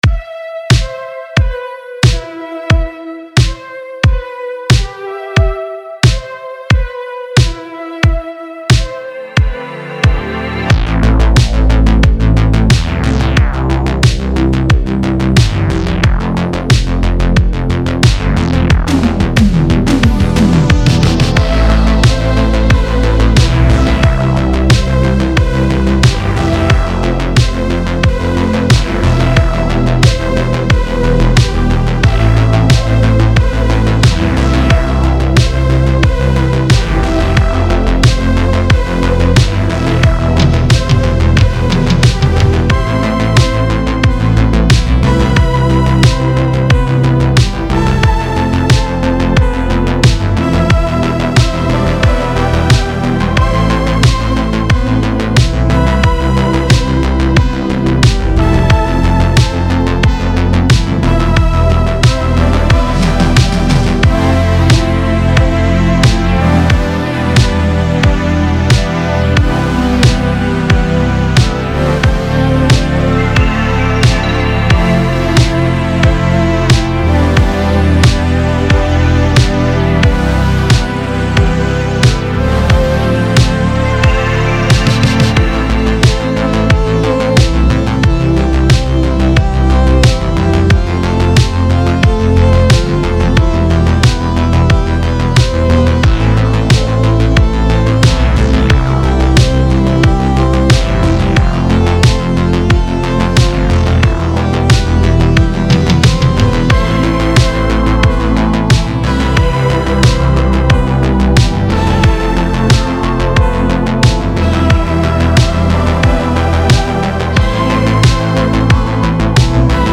SYNTHWAVE DRIVER RETRO
Nostalgia / Retro / 80s-90s / Film